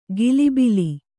♪ gilibili